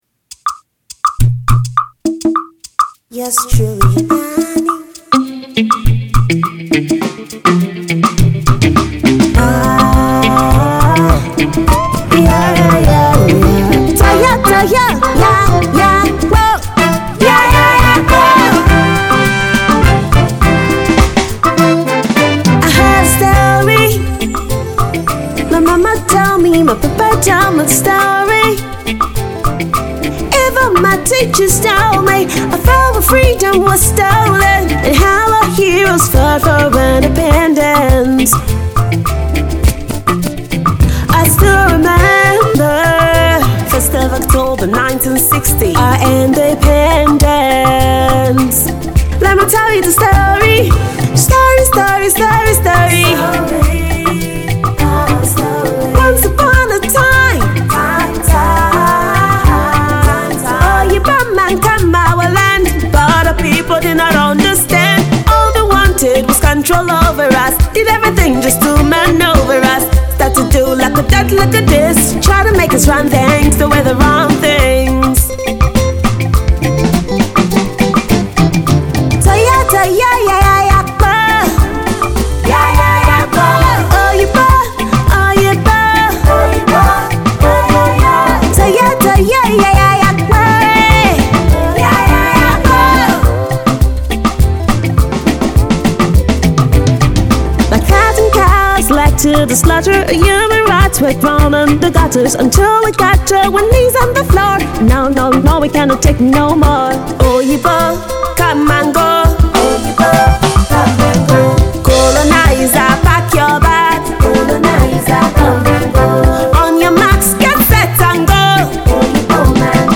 celebratory song